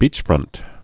(bēchfrŭnt)